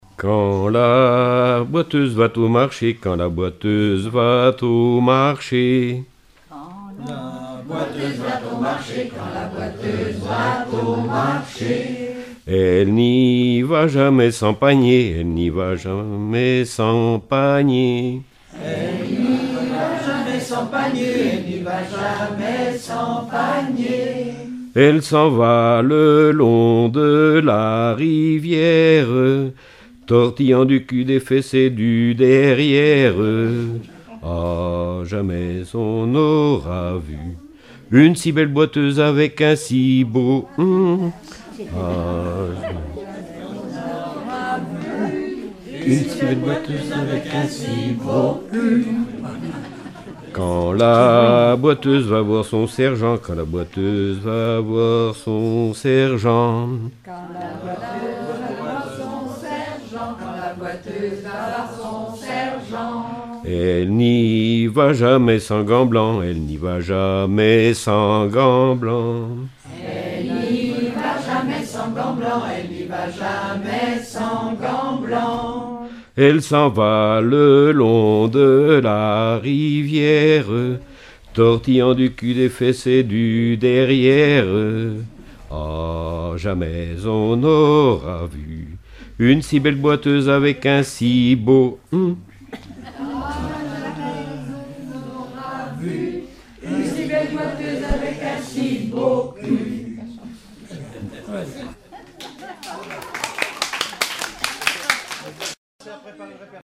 Genre énumérative
Répertoire de chansons populaires et traditionnelles
Catégorie Pièce musicale inédite